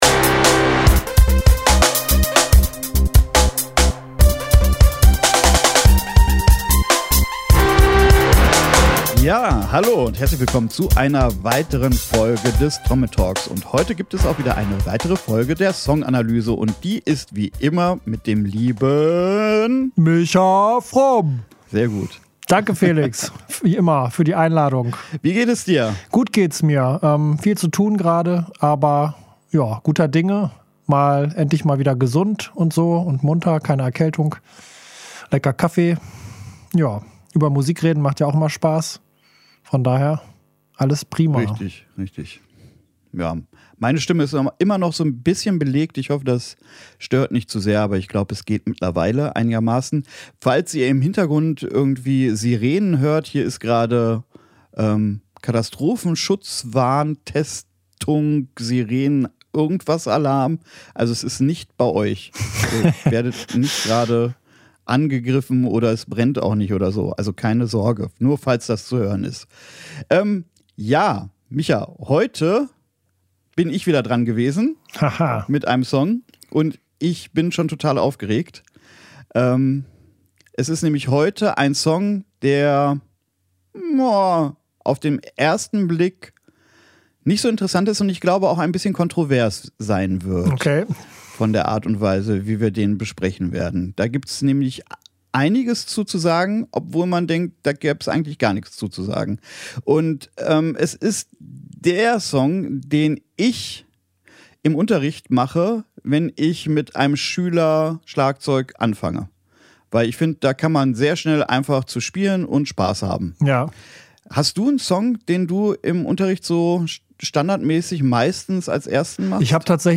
Trommel Talk